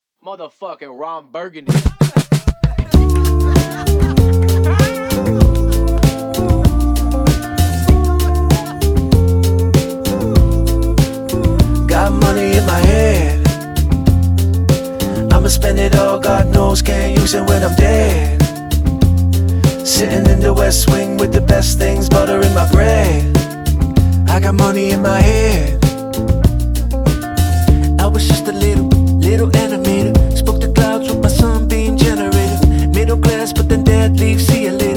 Жанр: Поп / Инди
# Indie Pop